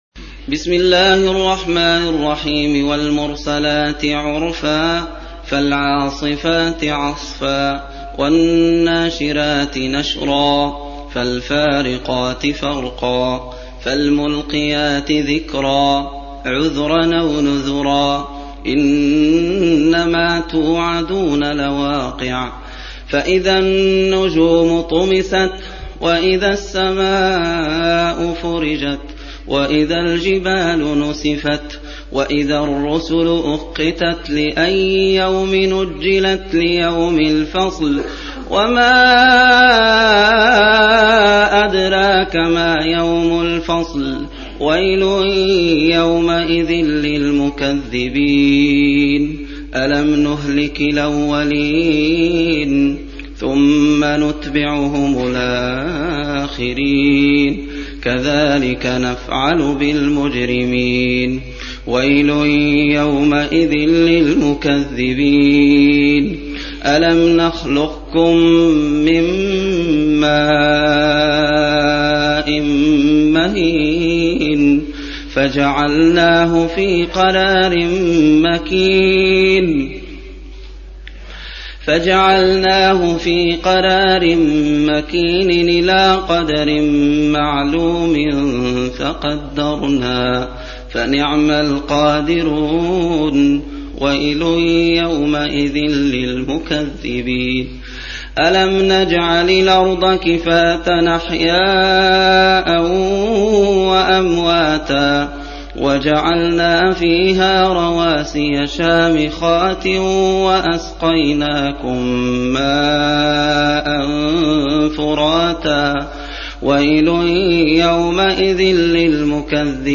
77. Surah Al-Mursal�t سورة المرسلات Audio Quran Tarteel Recitation
حفص عن عاصم Hafs for Assem
Surah Sequence تتابع السورة Download Surah حمّل السورة Reciting Murattalah Audio for 77. Surah Al-Mursal�t سورة المرسلات N.B *Surah Includes Al-Basmalah Reciters Sequents تتابع التلاوات Reciters Repeats تكرار التلاوات